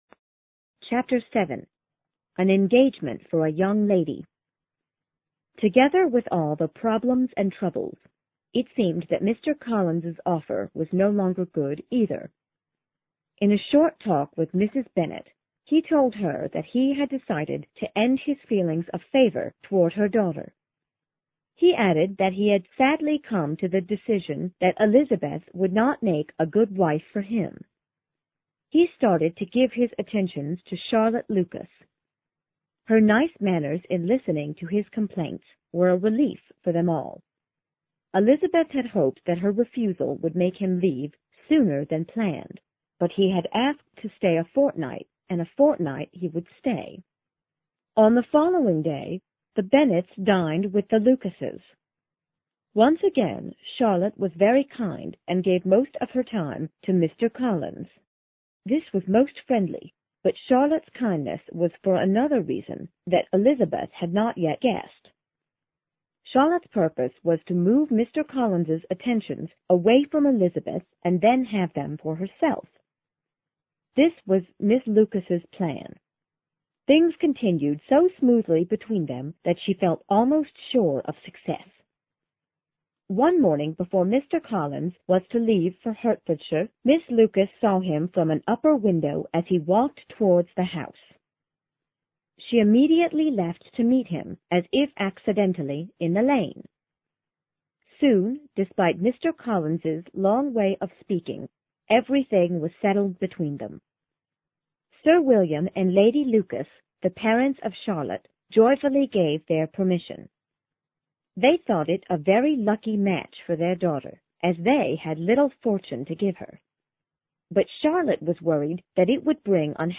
有声名著之傲慢与偏见 Chapter7 听力文件下载—在线英语听力室